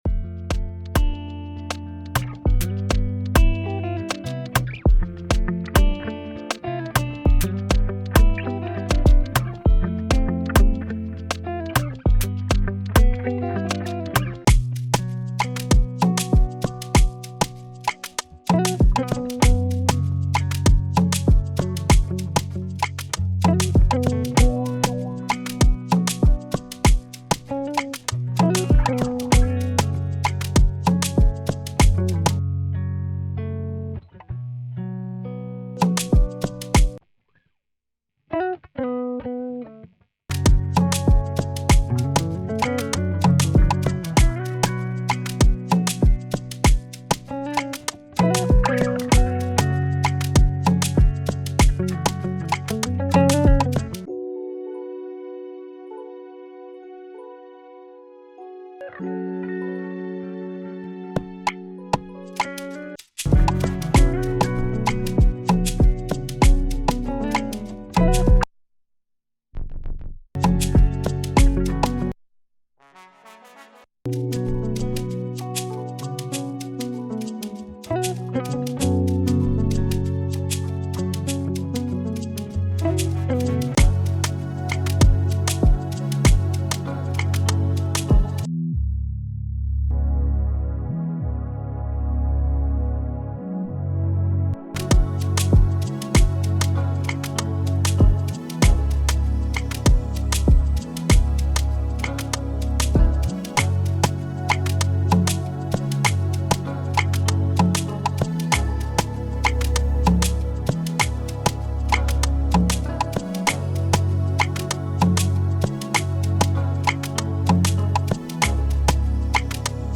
This pack comes with the latest sounds that’ll guarantee you High-Quality Beats, with minimal adjustments when mixing.
Flex-N-Chill-AfroBeats-x-Guitars-All-In-One-Pack.mp3